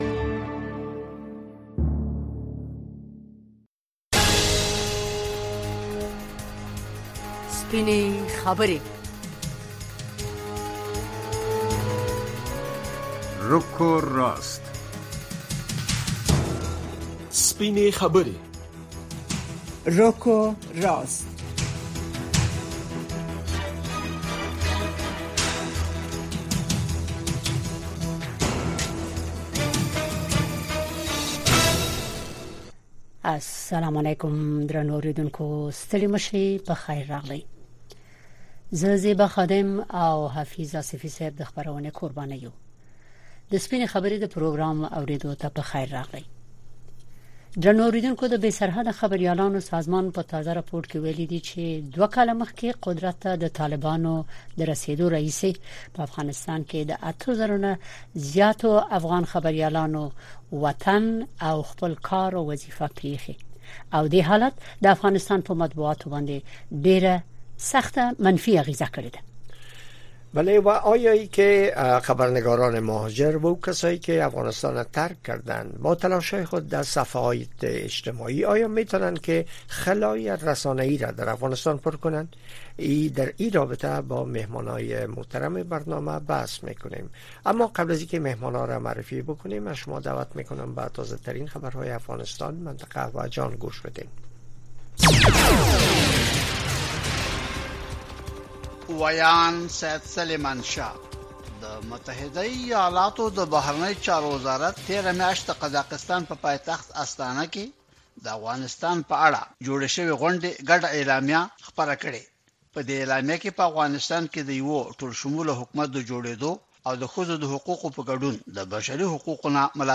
د ورځې په خبرونو اومسایلو د نظر د خاوندانو سپینې خبرې او د اوریدونکو نظرونه